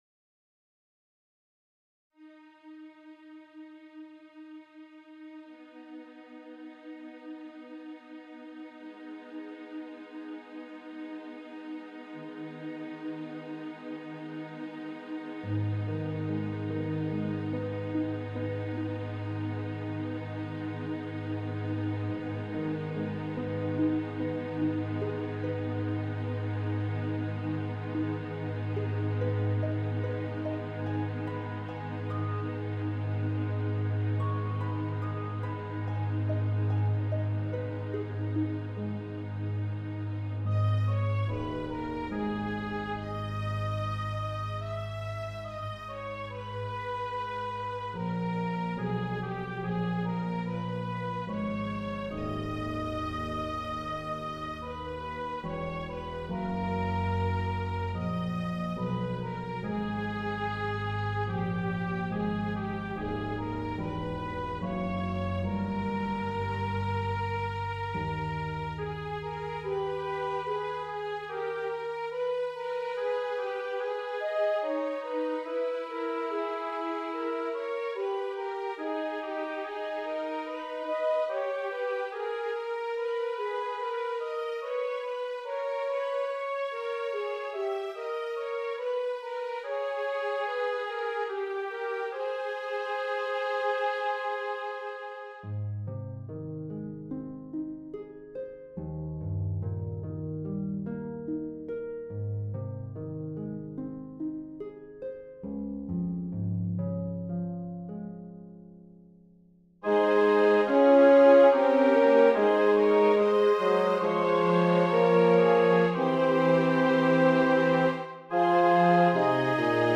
It's from when I used to write with a more baroque style.